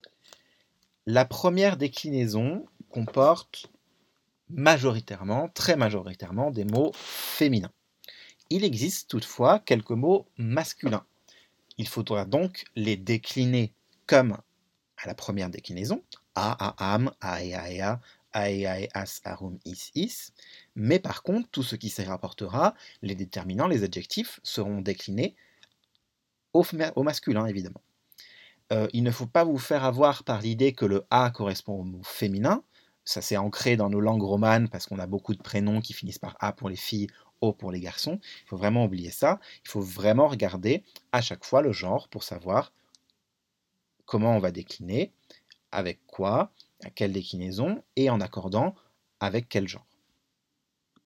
Mémos vocaux pour le latin
Pardon par avance pour les petites hésitations, erreurs de langues et autres zozotements ! =)